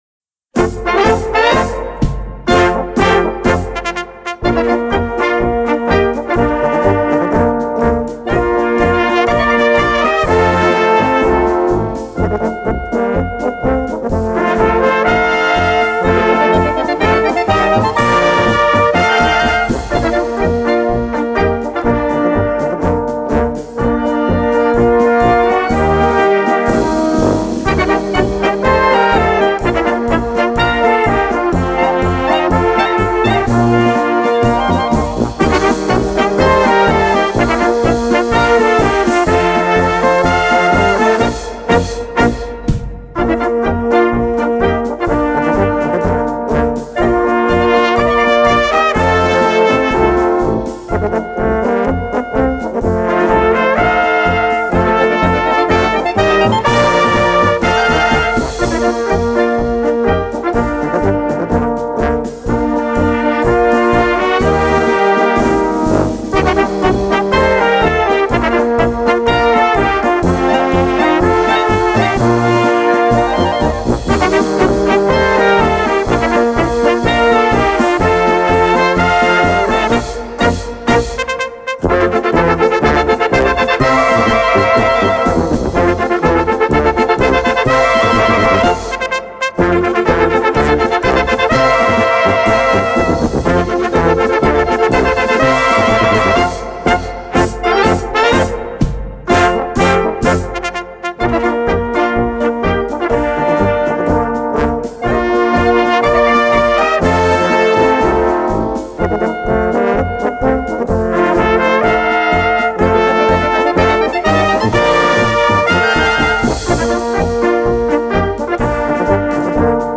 Category Concert/wind/brass band
Subcategory Polka
Instrumentation Ha (concert/wind band)